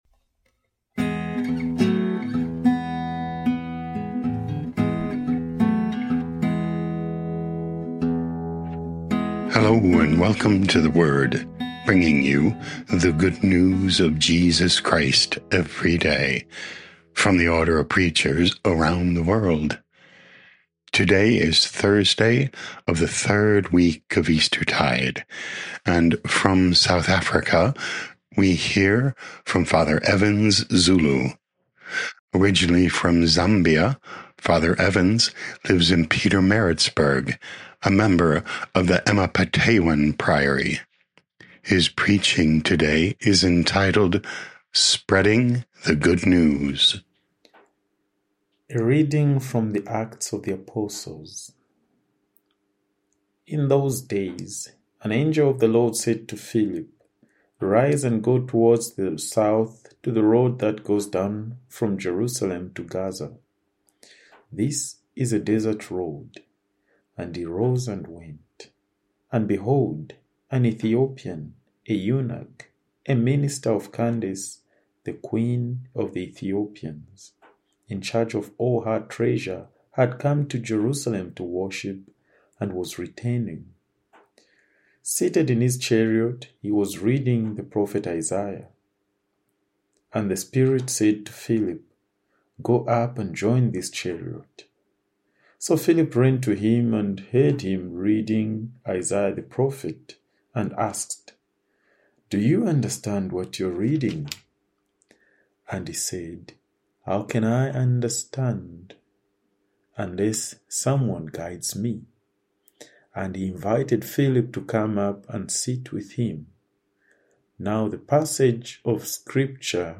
23 Apr 2026 0 Comments Spreading the Good News Podcast: Play in new window | Download For 23 April 2026, Thursday of the 3rd week of Eastertide, based on Acts 8:26-40, sent in from Pietermaritzburg, South Africa.